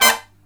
HIGH HIT13-L.wav